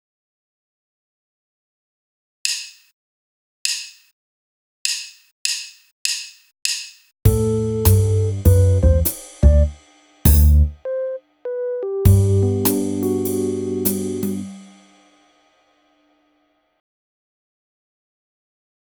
Arrangement format: Piano Solo
Instruments: Piano Genre: Jazz